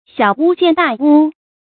小巫见大巫 xiǎo wū jiàn dà wū
小巫见大巫发音